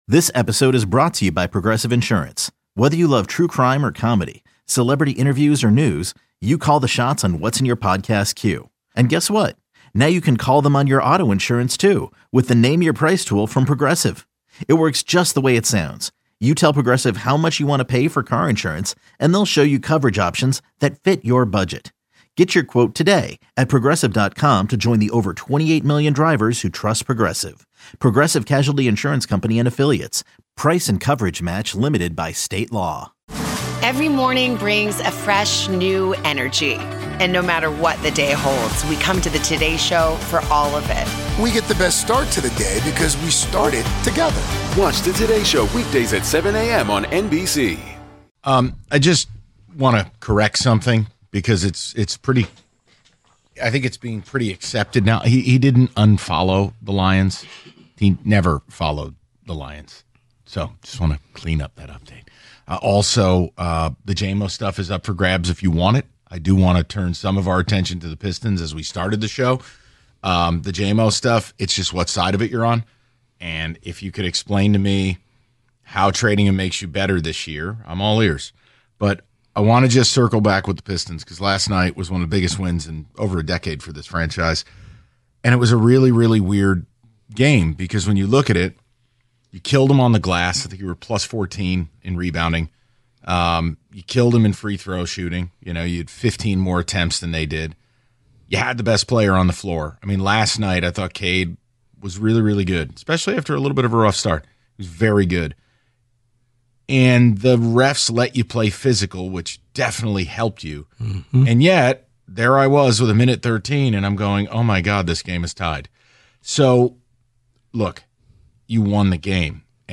Then, they wrap up the show with the "Big Finish" ahead of Tigers baseball.